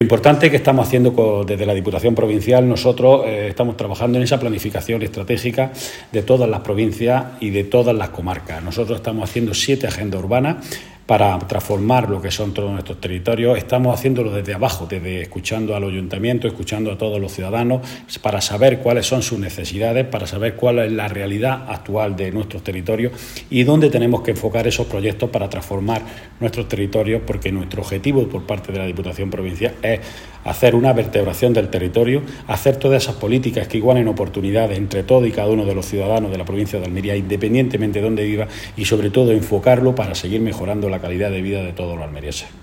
El diputado José Juan Martínez explica los avances en agenda urbana que se han logrado y las actuaciones que se están realizando
24-10_FAMP_diputado.mp3